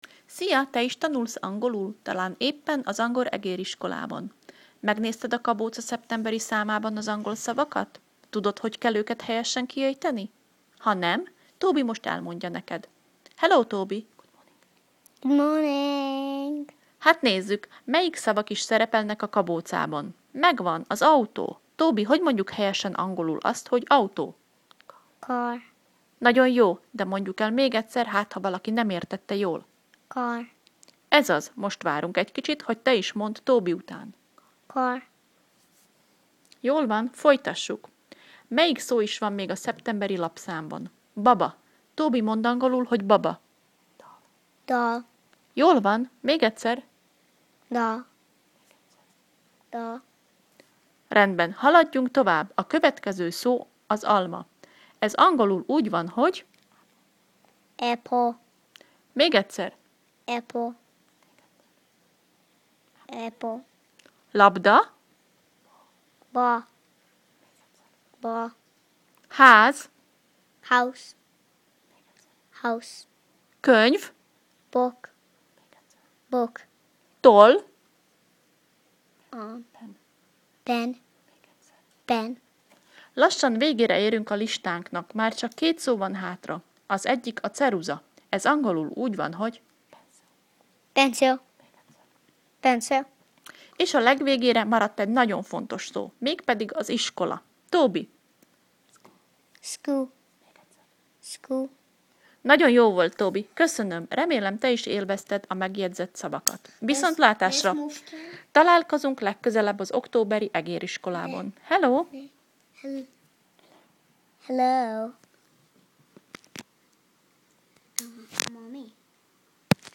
Hallgasd meg Tobytól a szavak helyes kiejtését